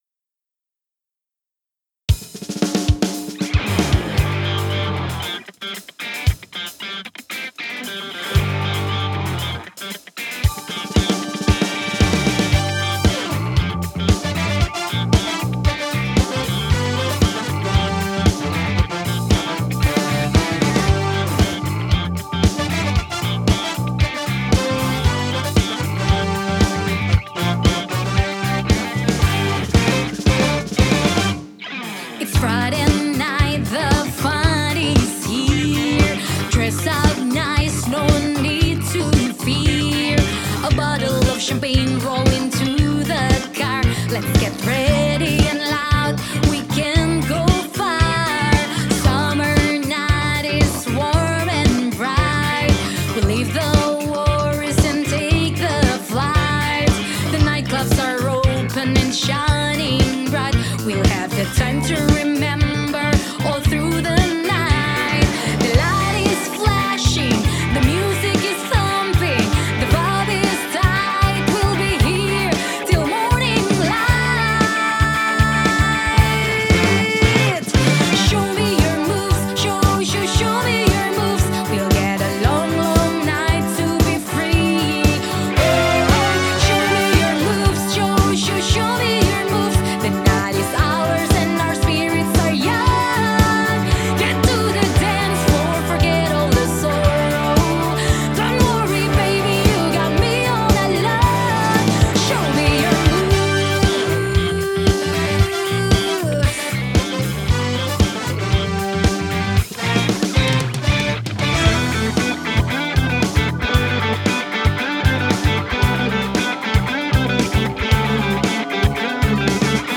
It’s the ultimate feel-good anthem for your good day!